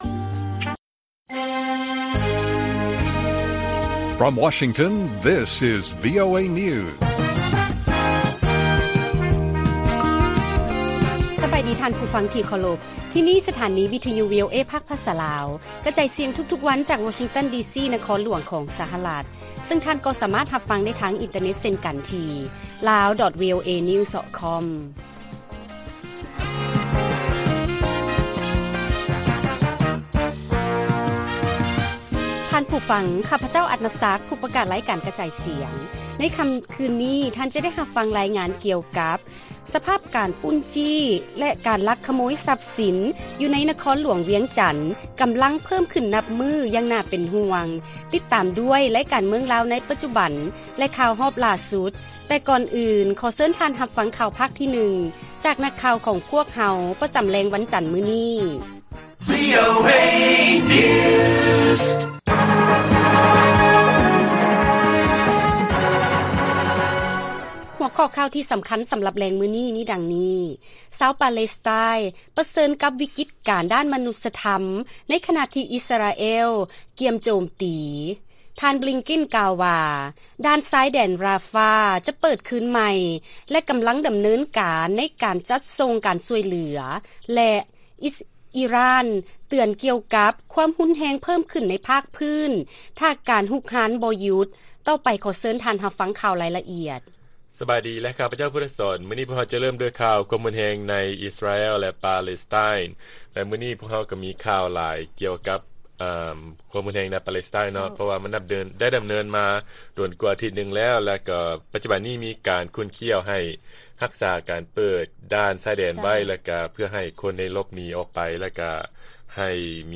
ວີໂອເອພາກພາສາລາວ ກະຈາຍສຽງທຸກໆວັນ, ຫົວຂໍ້ຂ່າວສໍາຄັນໃນມື້ນີ້ມີ: 1. ຊາວປາແລັສໄຕນ໌ ປະເຊີນກັບວິກິດການດ້ານມະນຸດສະທຳ, 2. ທ່ານ ບລິງເກັນ ກ່າວວ່າ ດ່ານຊາຍແດນຣາຟາ ຈະເປີດຄືນໃໝ່ ແລະ ກຳລັງດຳເນີນການ ໃນການຈັດສົ່ງການຊ່ວຍເຫຼືອ, ແລະ 3. ອີຣ່ານ ເຕືອນ ກ່ຽວກັບ ຄວາມຮຸນແຮງເພີ່ມຂຶ້ນໃນພາກພື້ນຖ້າ ‘ການຮຸກຮານບໍ່ຢຸດ.’